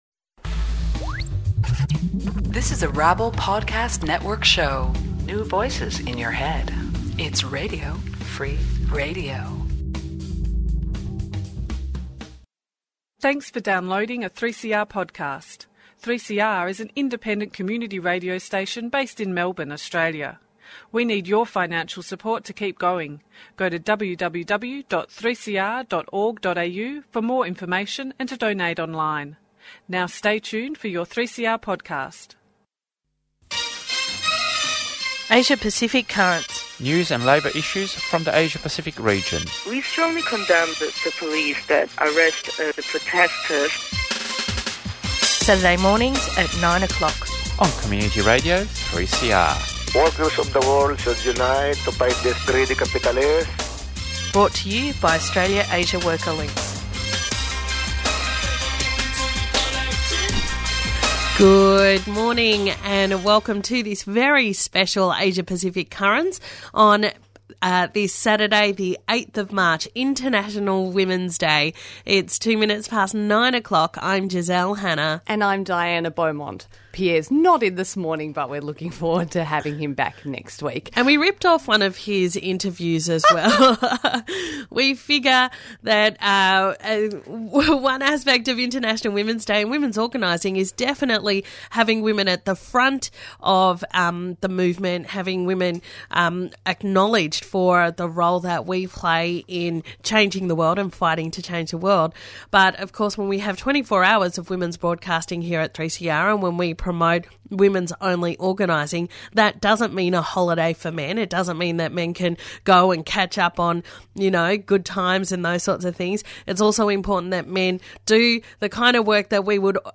Download MP3 Asia Pacific Currents International Women's Day in the Asia Pacific March 8, 2014 | International Women's Day edition of Asia Pacific Currents: Labour news from the Asia Pacific region and an interview about the Korean railway workers' strike.